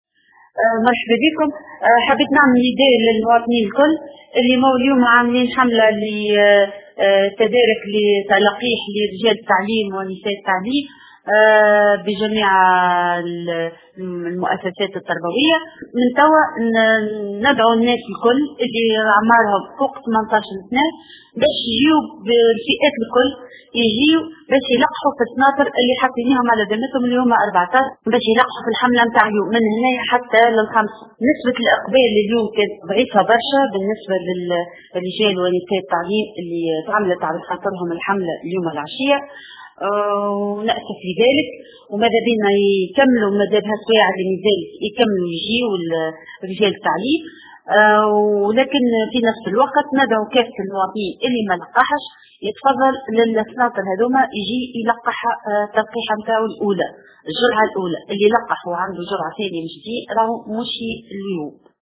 في تصريح للجوهرة اف أم